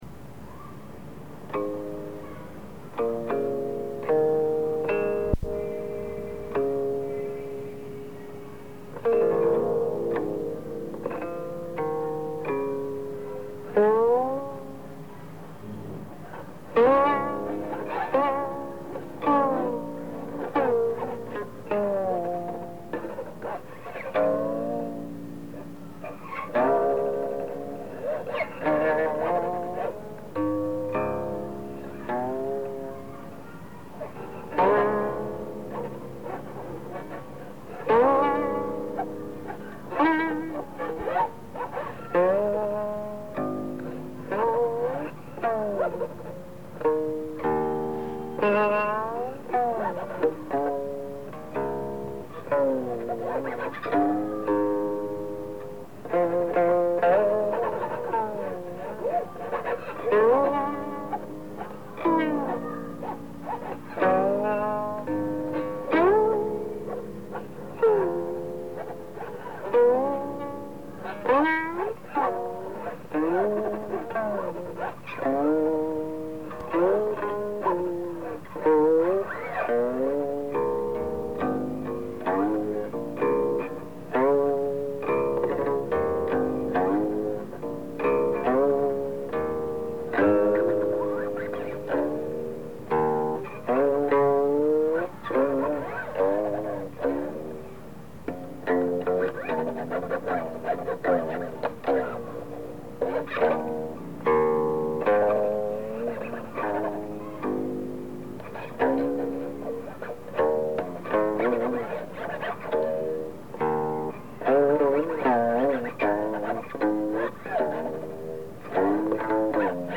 Elle l’interprétait avec une étonnante liberté: rien de guindé dans son jeu. Pas non plus de pathos dans son interprétation — malgré le sujet qui pourrait s’y prêter—- mais une énergie prodigieuse et beaucoup de finesse.